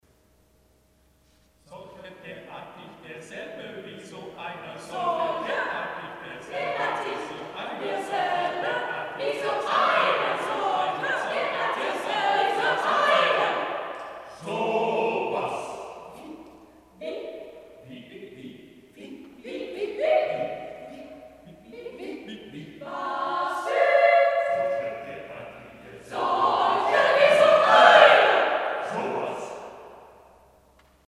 Opnames van het Erasmus Kamerkoor
Opnames concerten februari 2007 (project Noorderlicht)